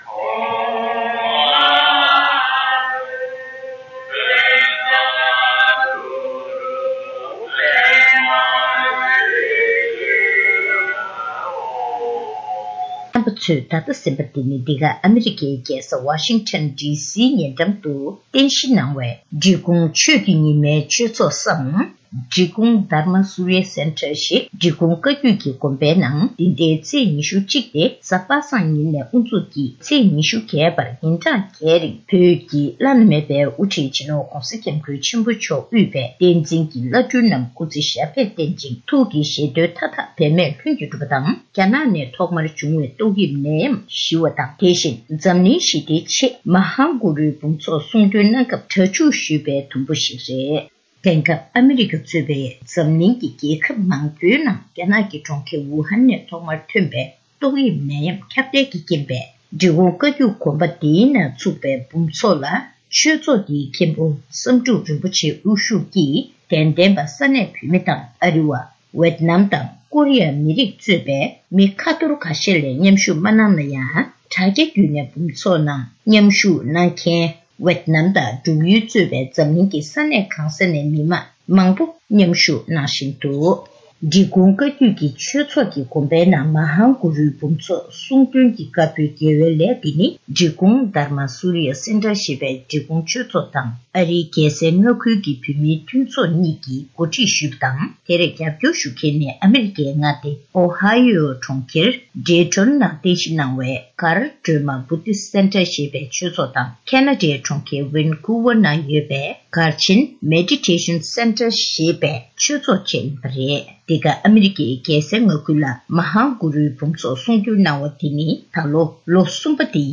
གནས་འདྲི་ཞུས་ནས་ཕྱོགས་སྒྲིགས་དང་སྙན་སྒྲོན་ཞུས་པར་གསན་རོགས་ཞུ།།